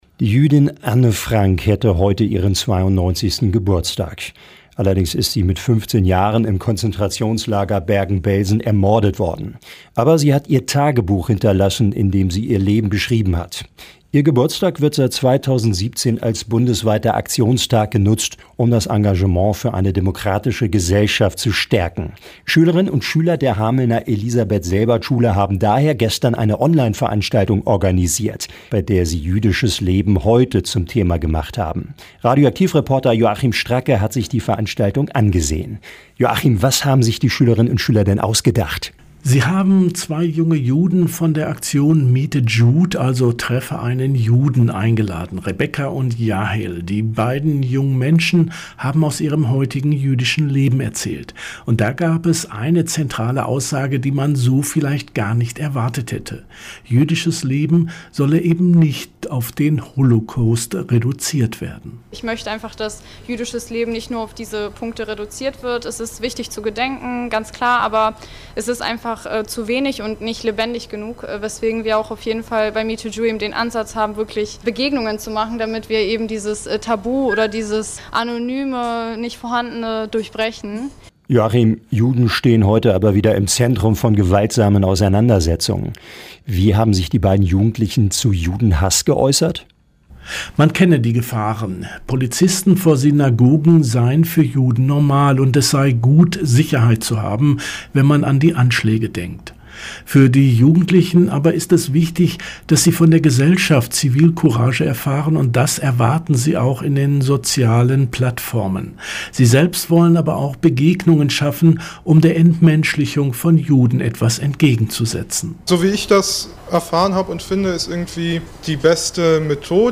Landkreis Hameln-Pyrmont: JUNGE JUDEN IM GESPRAECH